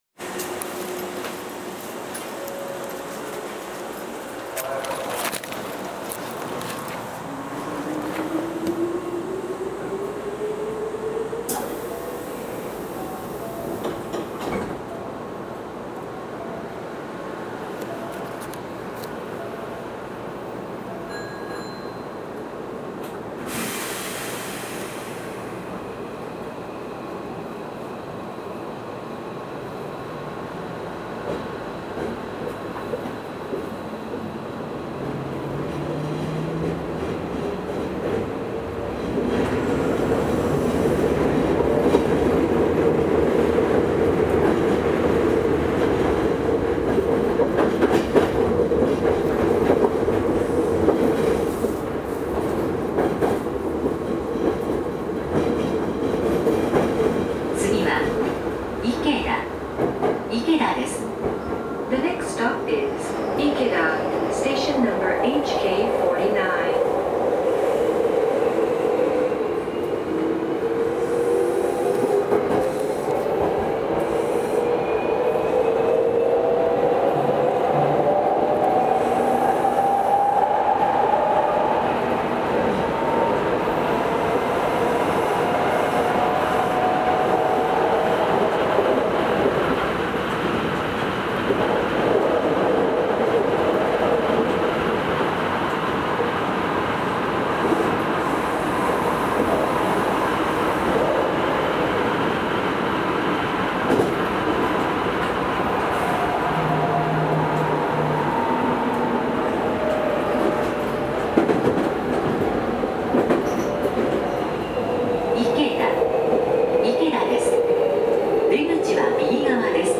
走行音
VVVFインバータ制御
録音区間：川西能勢口～池田(急行)(お持ち帰り)
Hnq7000-VVVF.mp3